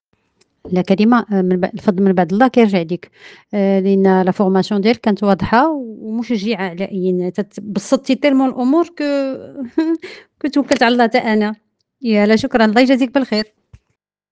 شهادات صوتية لبعض المشاركات 👇👇👇